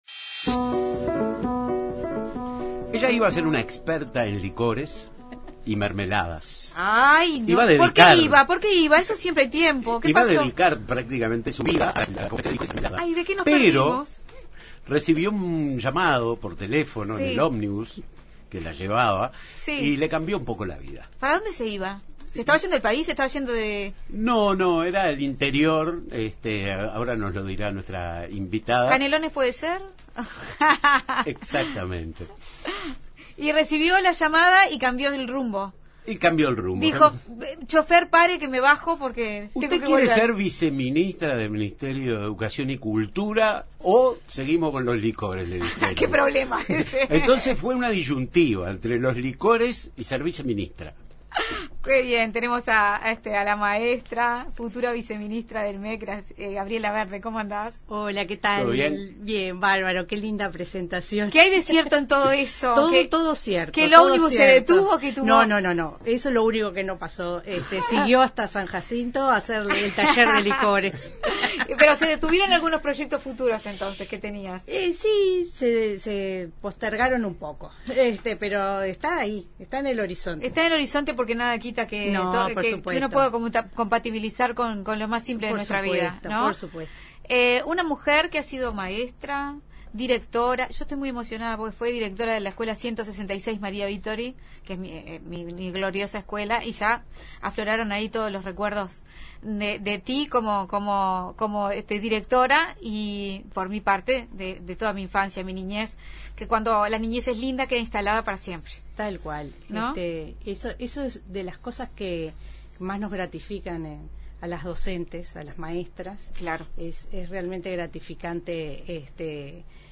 Recibimos a la viceministra electa del MEC, Gabriela Verde
Hoy en Buenas Tardes Uruguay, la viceministra electa del MEC, Gabriela Verde, compartió los principales ejes que guiarán su gestión al frente del Ministerio.